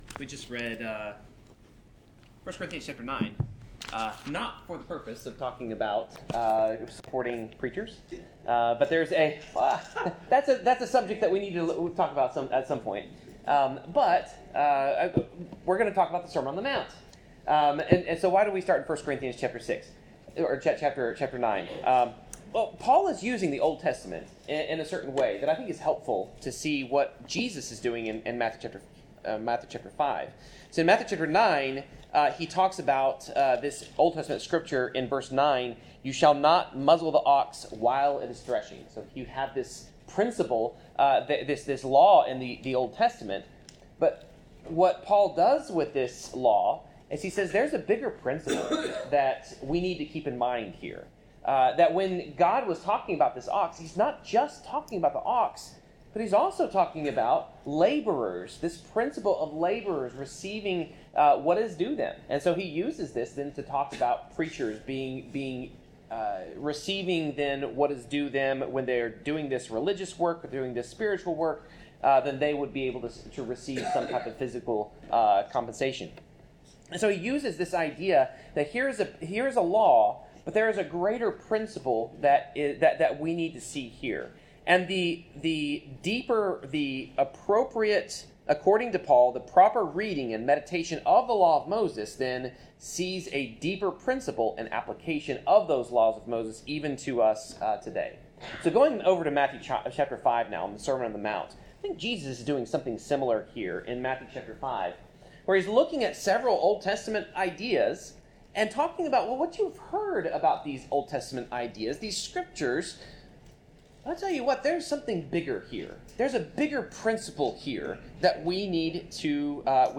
Passage: Matthew 5:27-30 Service Type: Sermon